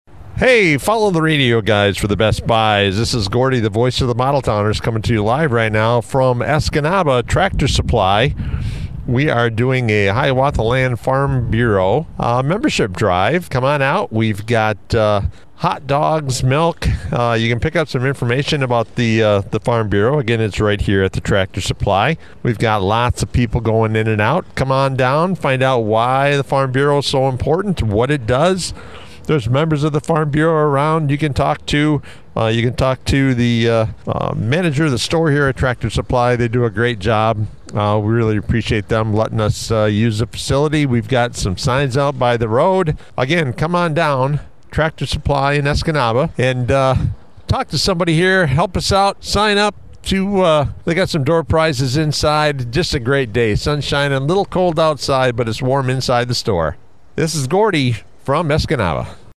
It looked to be a hectic day, as more and more people flooded into the Escanaba Tractor Supply Company’s showroom!